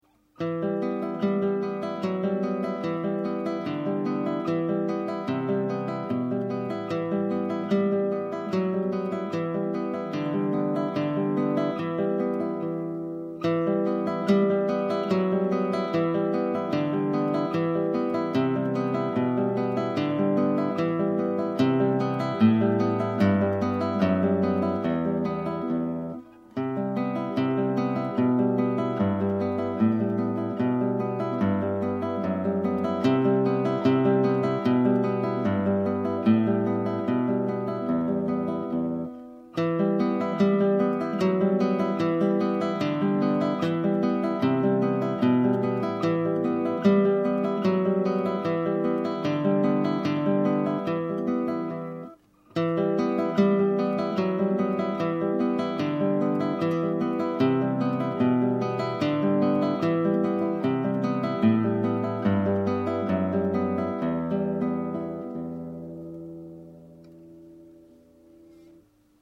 Tintelende Vingers Dit stuk is vooral een oefening voor de arpeggio aanslag. De melodie speel je met de duim.
De begeleiding met de vingers i, m en a op de 3 hoge snaren moet vooral niet te hard gespeeld worden.